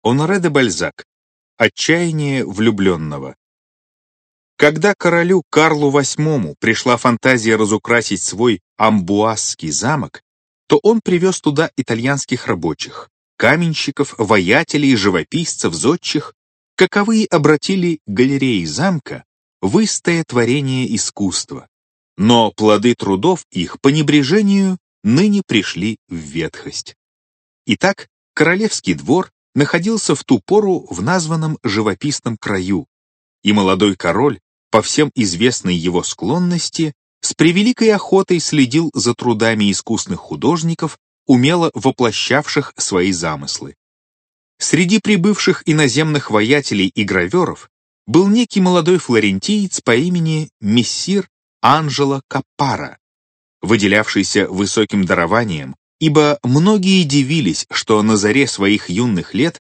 Аудиокнига Красавица Империя. Рассказы | Библиотека аудиокниг